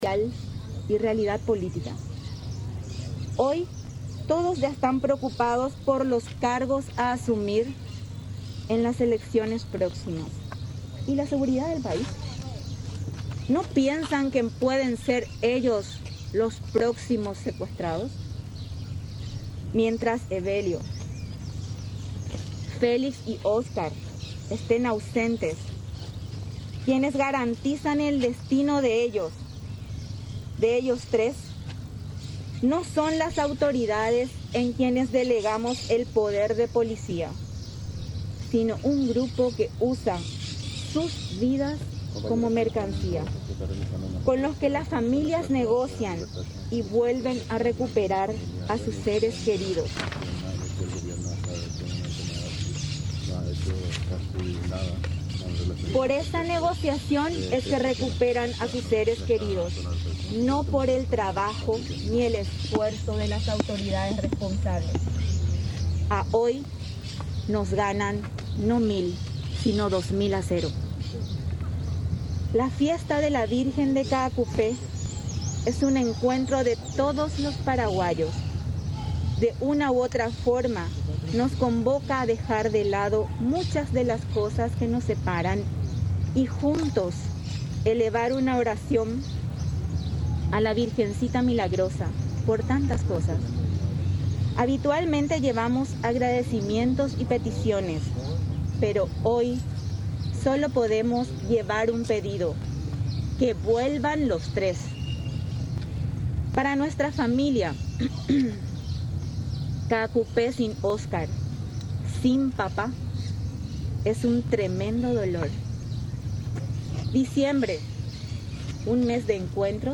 en conferencia de prensa.